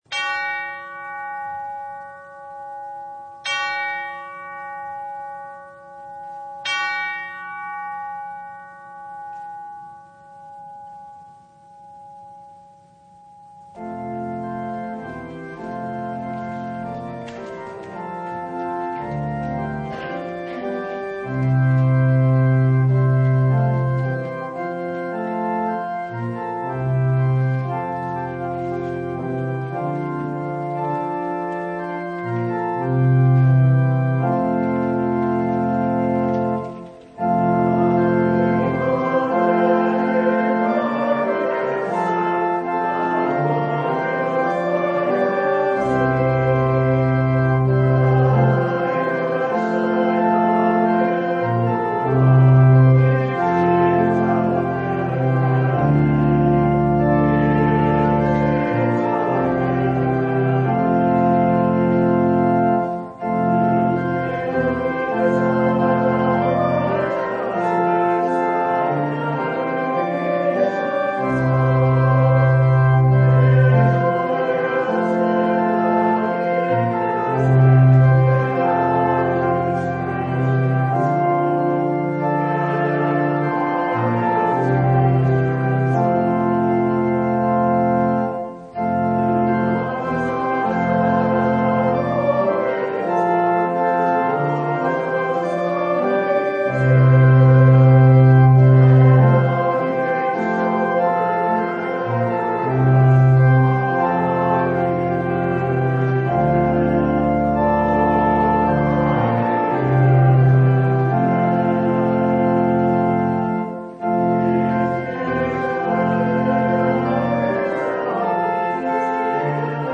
Mark 1:21-28 Service Type: Sunday Christ